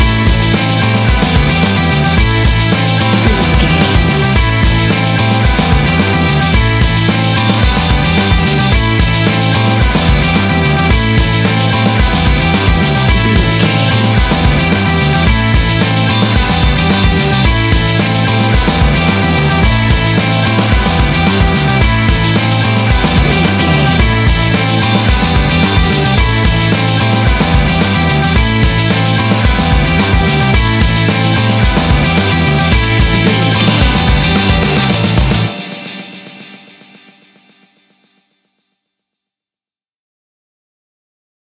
Ionian/Major
Fast
energetic
driving
happy
bright
electric guitar
bass guitar
drums
hard rock
distortion
rock instrumentals
heavy drums
distorted guitars
hammond organ